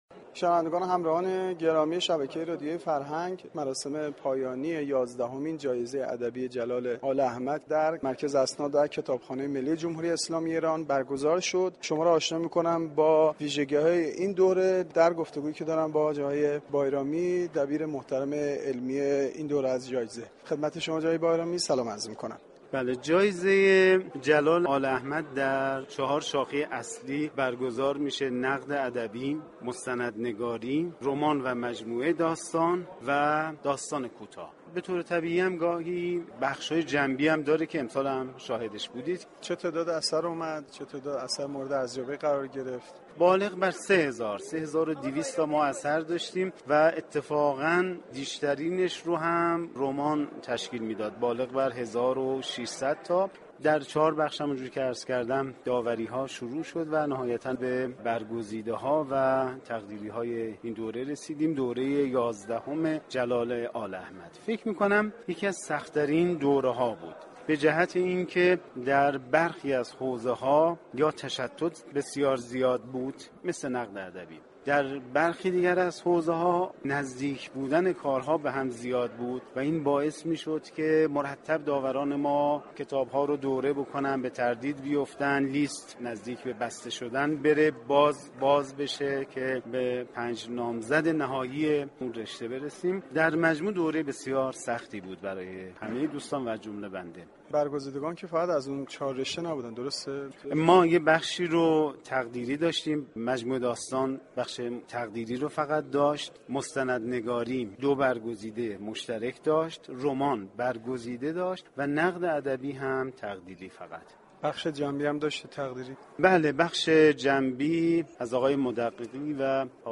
مراسم اختتامیه ی یازدهمین جایزه ی ادبی جلال آل احمد در سالن سازمان اسناد و كتابخانه ی ملی برگزار شد .